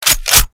It's taken from a SAW249 reload.
saw_lever.mp3